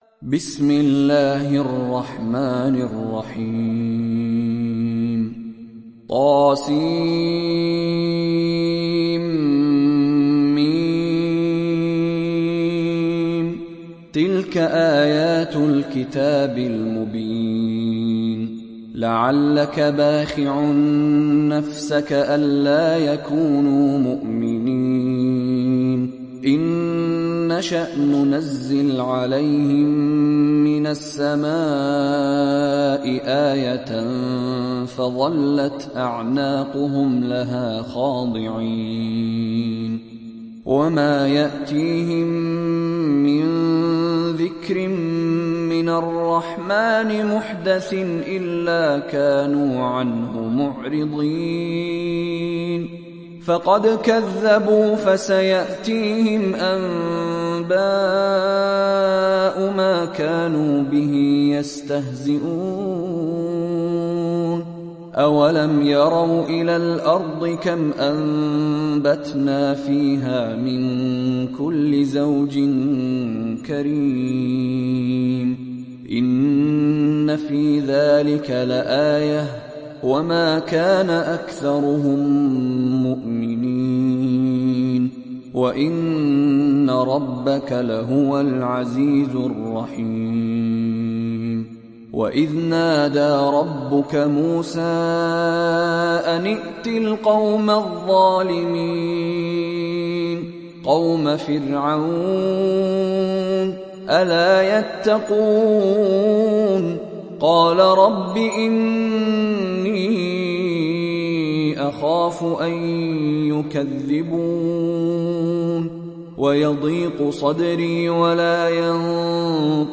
Sourate Ash Shuara Télécharger mp3 Mishary Rashid Alafasy Riwayat Hafs an Assim, Téléchargez le Coran et écoutez les liens directs complets mp3
Moratal